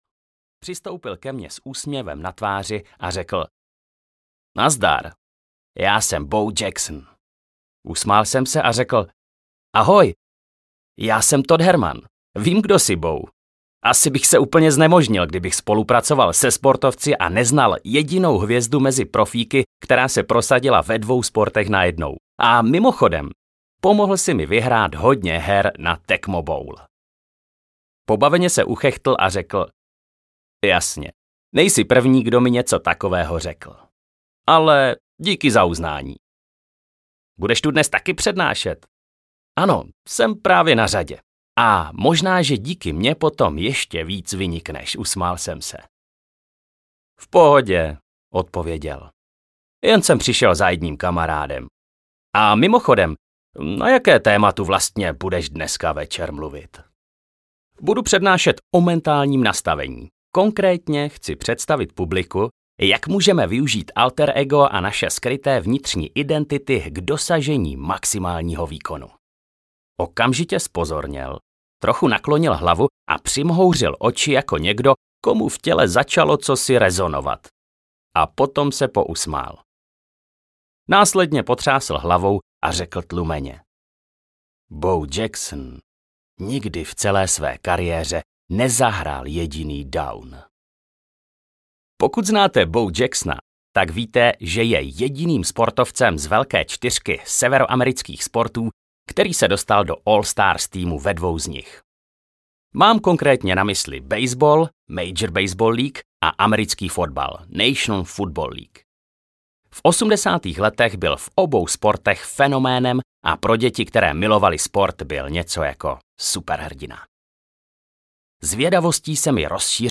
Alter ego efekt audiokniha
Ukázka z knihy